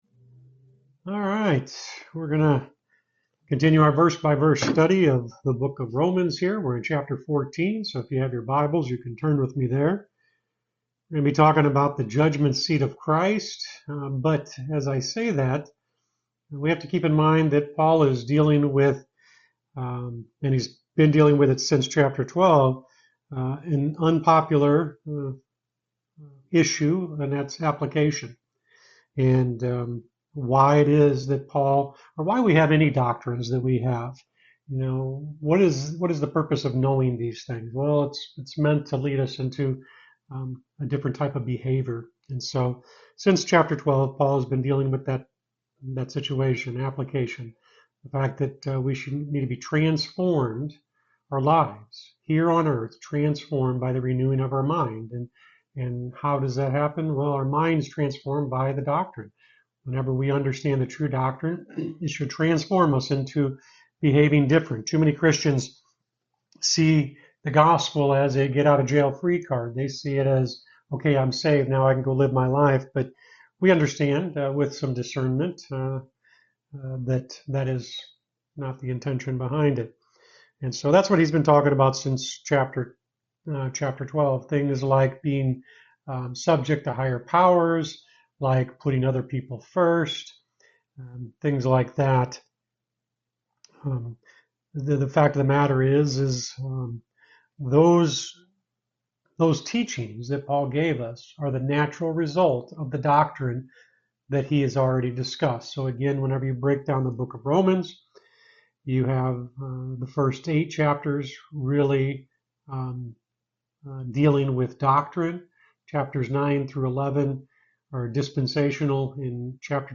When you're done, explore more sermons from this series.